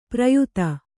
♪ prayuta